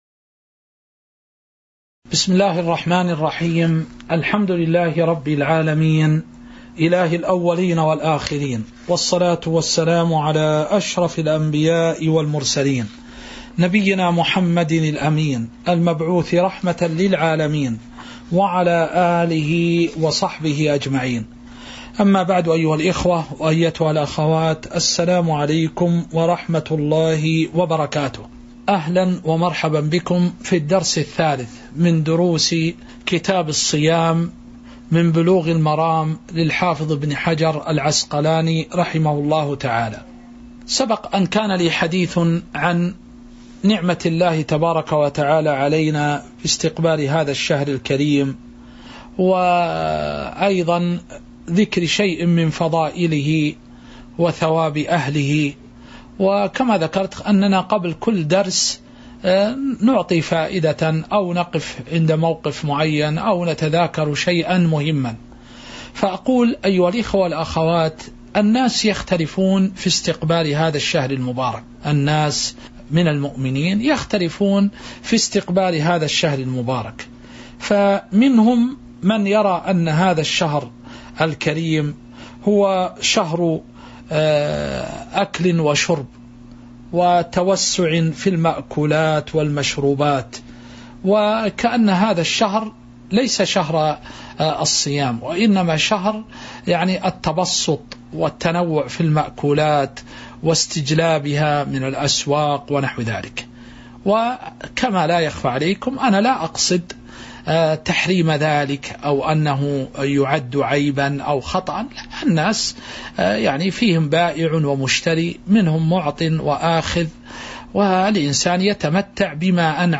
تاريخ النشر ٢٨ شعبان ١٤٤٤ هـ المكان: المسجد النبوي الشيخ